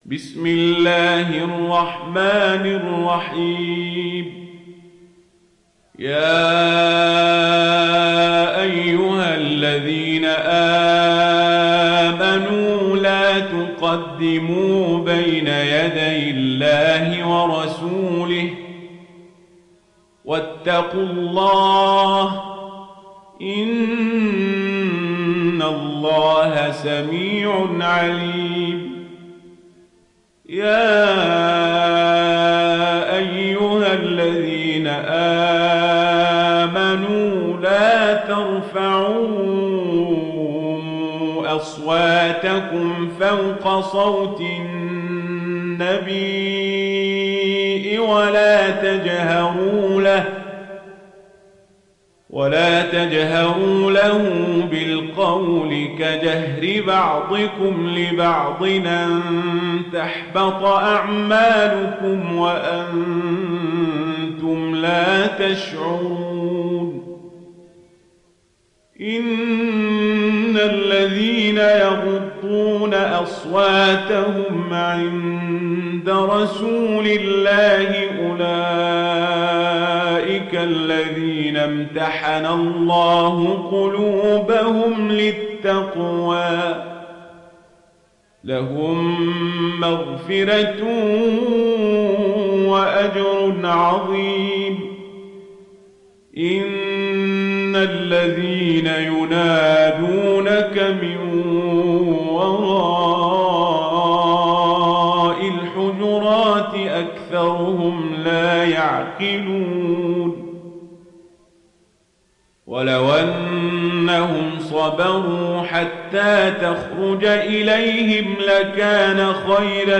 دانلود سوره الحجرات mp3 عمر القزابري روایت ورش از نافع, قرآن را دانلود کنید و گوش کن mp3 ، لینک مستقیم کامل